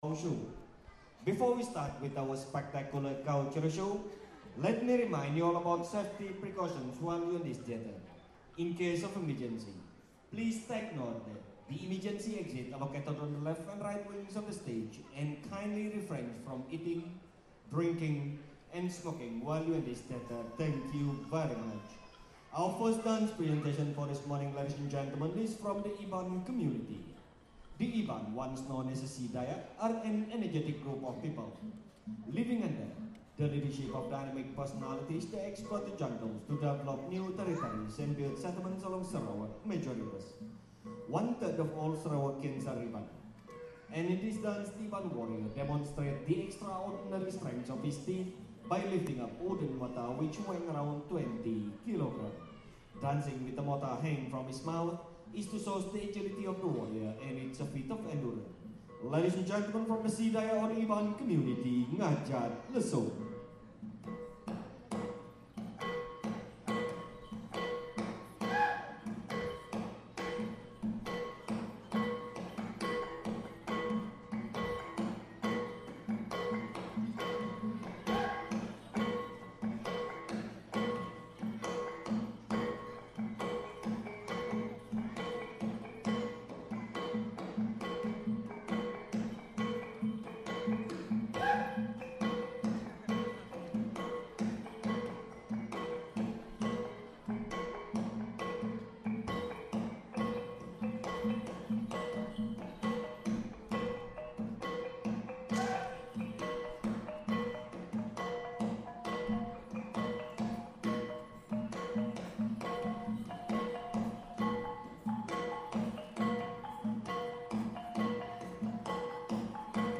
Iban dance